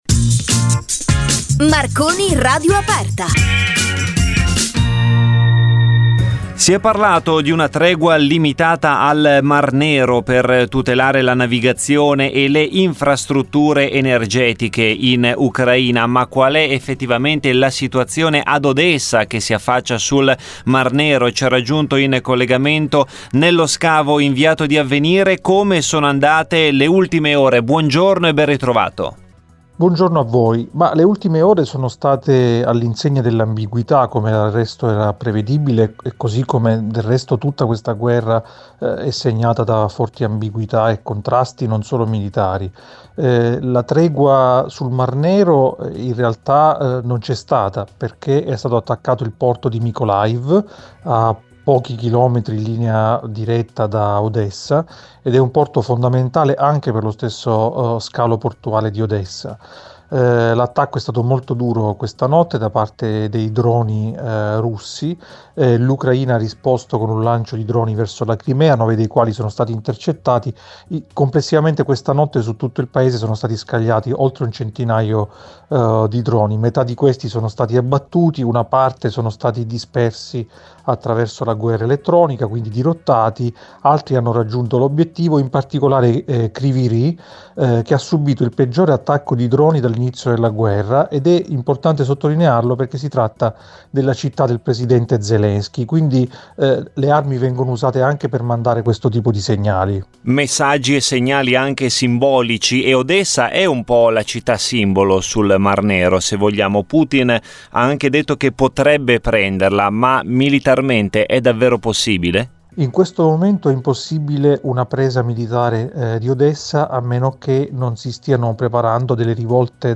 In collegamento da Odessa ne abbiamo parlato